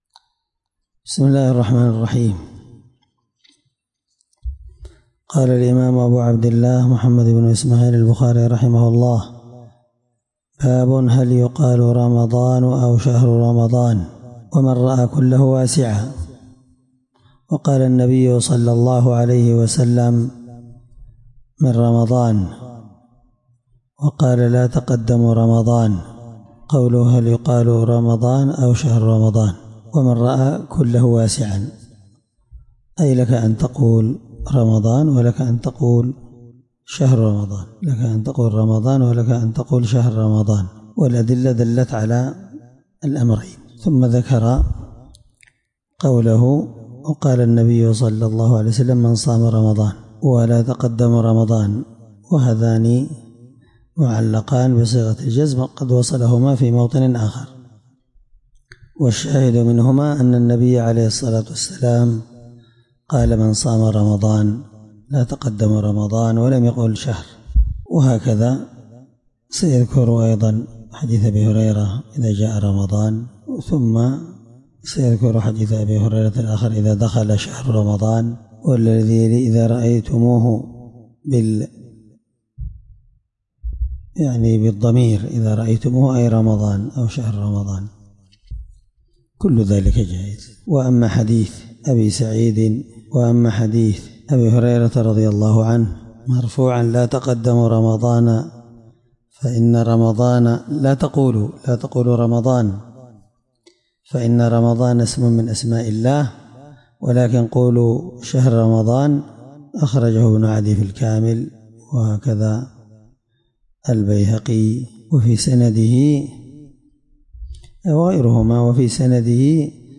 الدرس 6من شرح كتاب الصوم حديث رقم(1898-1900 )من صحيح البخاري